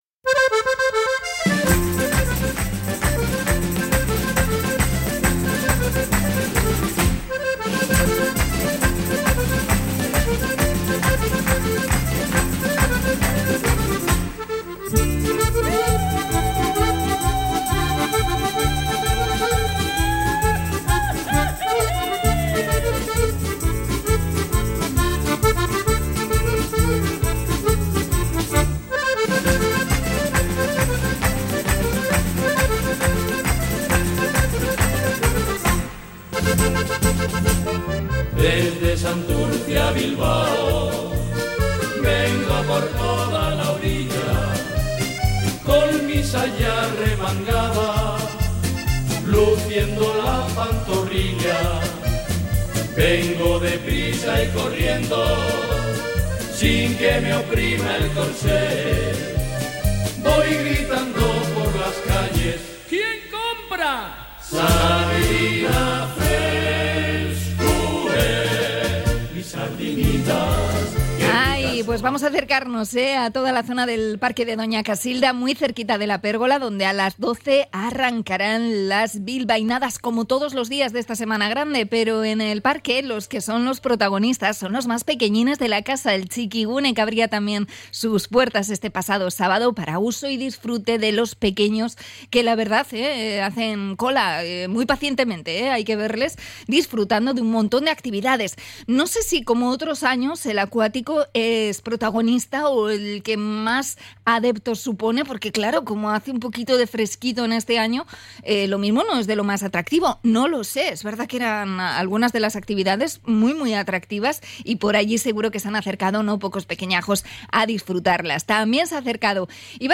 Conexión con el Txikigune de Aste Nagusia